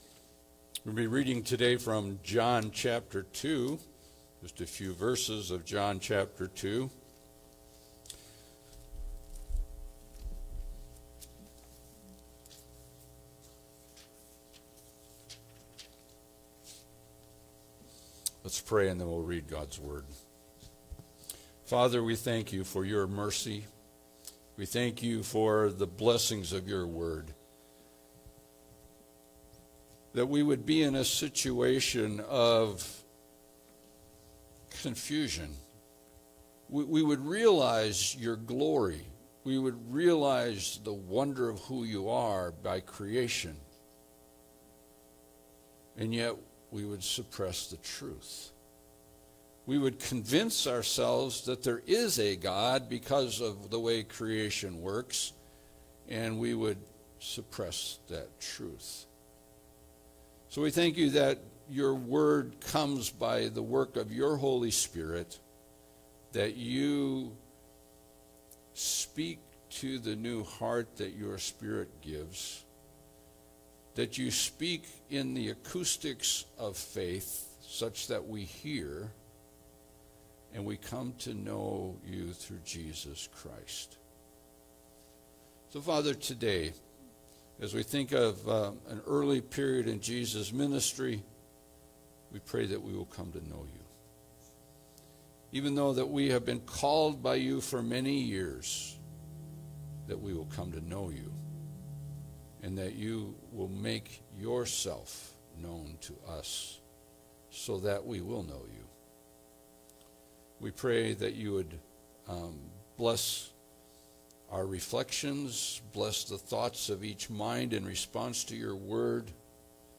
Passage: John 2:1-11 Service Type: Sunday Service